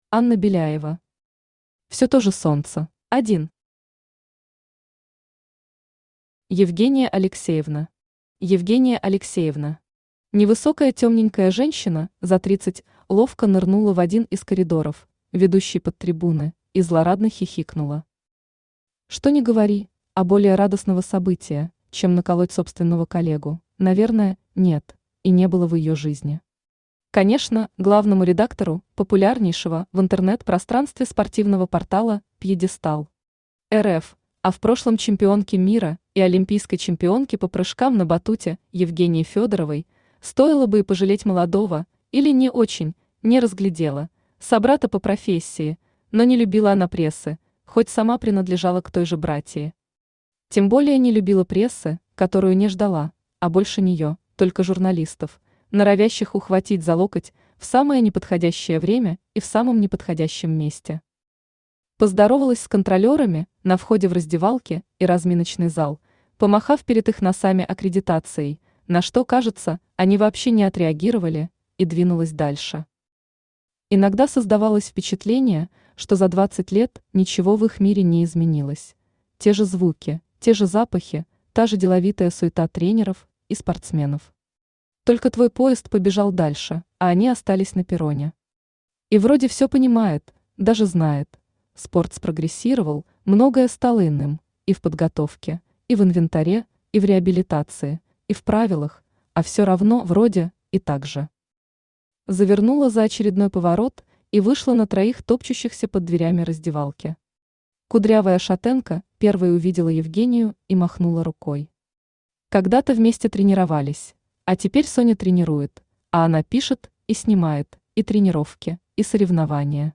Аудиокнига Все то же солнце | Библиотека аудиокниг
Aудиокнига Все то же солнце Автор Анна Беляева Читает аудиокнигу Авточтец ЛитРес.